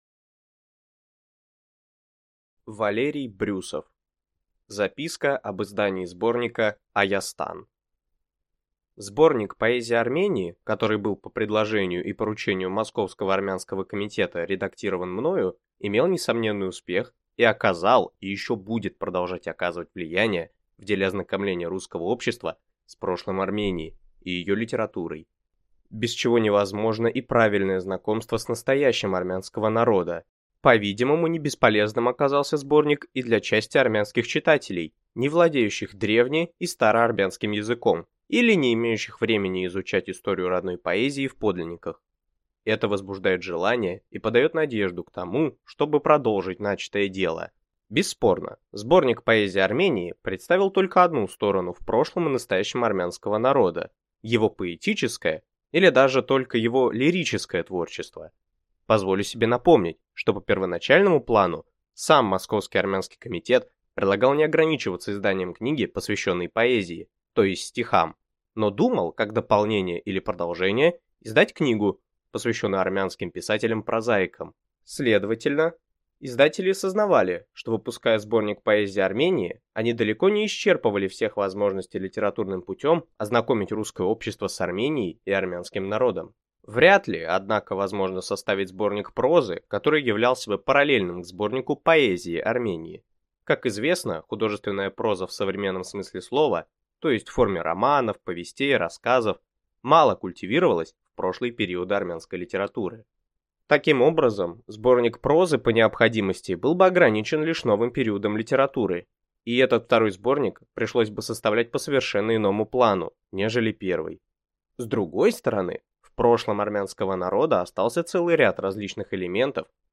Аудиокнига Записка об издании сборника «Айастан» | Библиотека аудиокниг